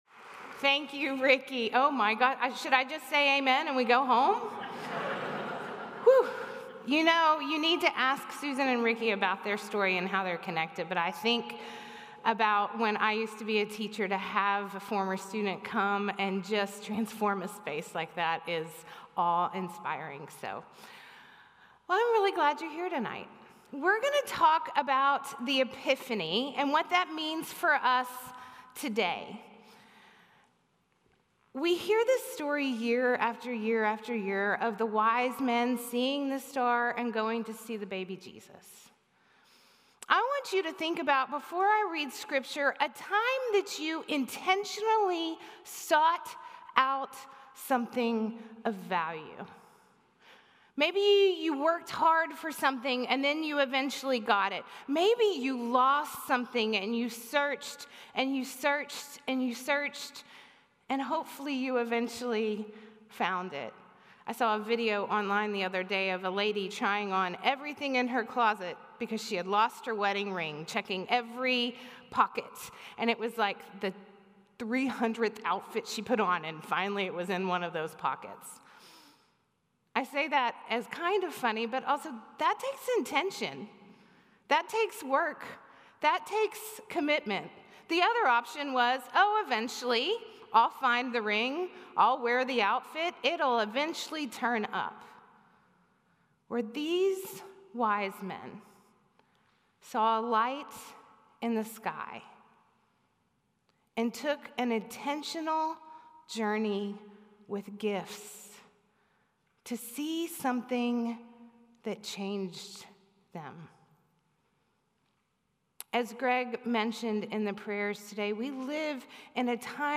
A message from the series "Advent."